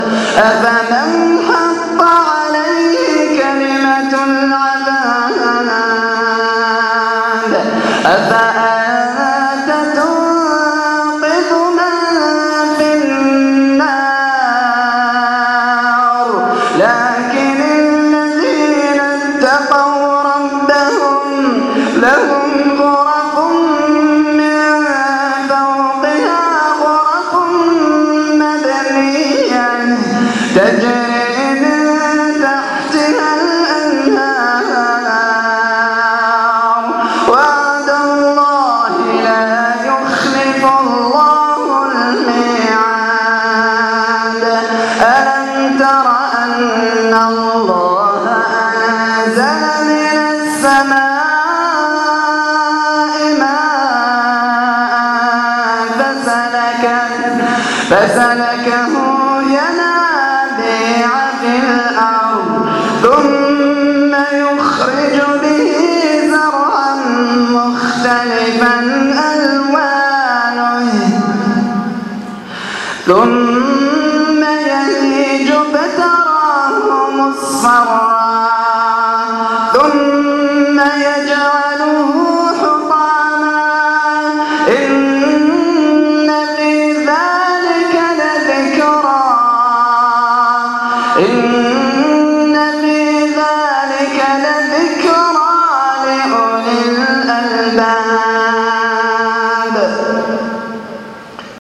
مقطع خاشع من صلاة التهجد